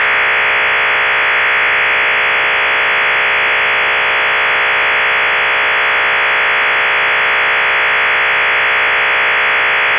Russian 93-tone OFDM modem idling Russian 93-tone OFDM modem sending tfc. back to PSK-systems page
RUS-OFDM93-idle.WAV